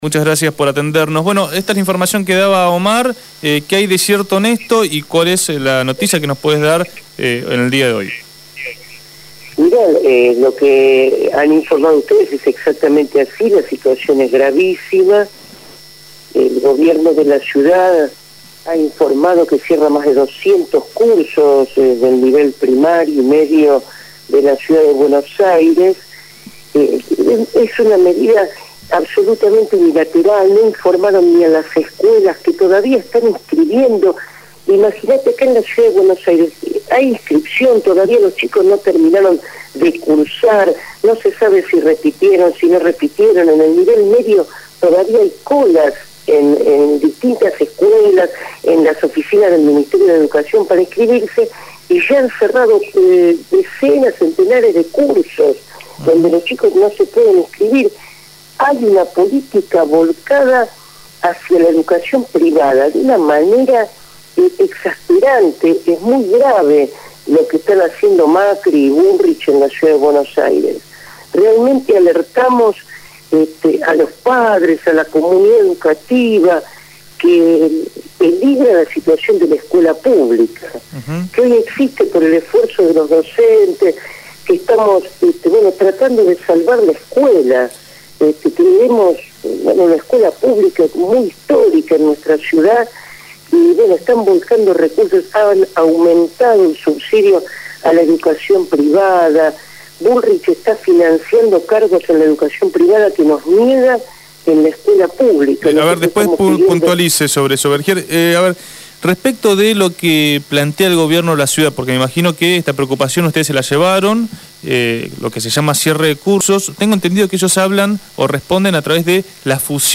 habló en Punto de Partida.